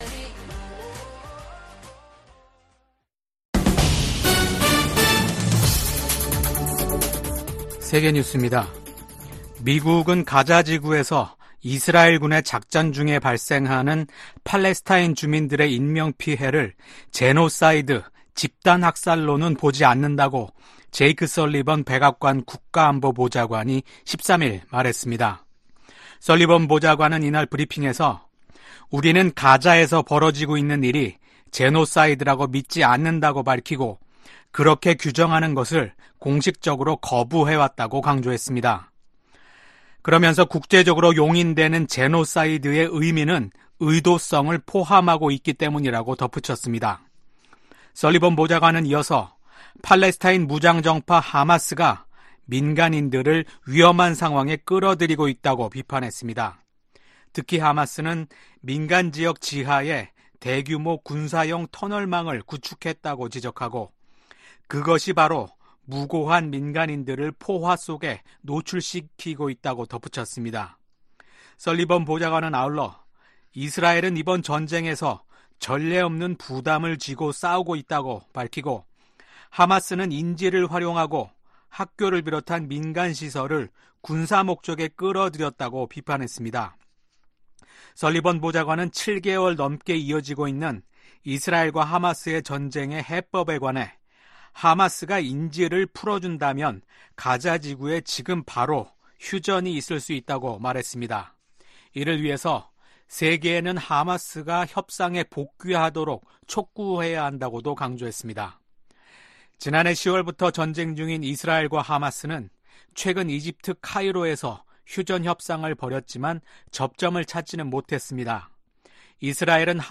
VOA 한국어 아침 뉴스 프로그램 '워싱턴 뉴스 광장' 2024년 5월 15일 방송입니다. 러시아가 철도를 이용해 북한에 유류를 수출하고 있다는 민간 기관 분석이 나온 가운데 실제로 북러 접경 지역에서 최근 열차 통행이 급증한 것으로 나타났습니다. 러시아가 올해 철도를 통해 25만 배럴의 정제유를 북한에 수출했다는 분석이 나온 데 대해 국무부가 북러 협력 심화에 대한 심각한 우려를 나타냈습니다.